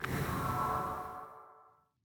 CandleDie.mp3